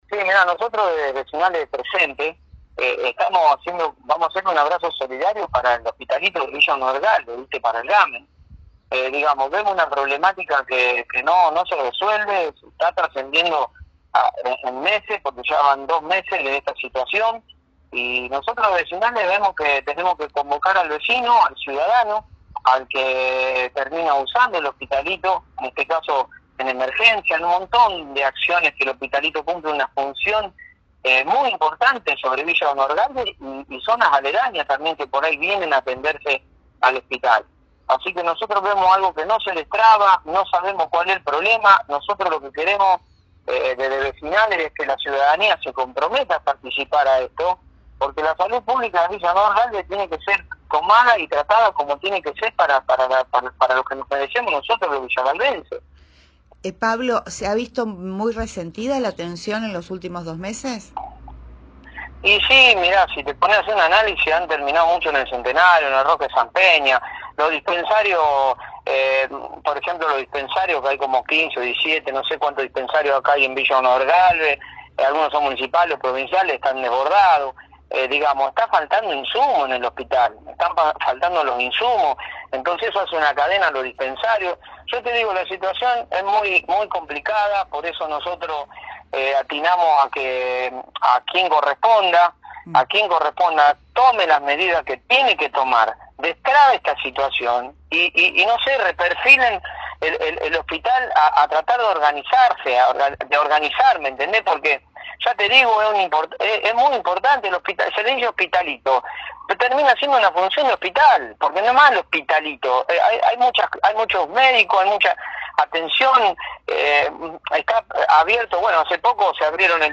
Villa Gobernador Gálvez, Primera Plana Villa Gobernador Gálvez, Primera Plana